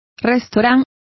Complete with pronunciation of the translation of restaurant.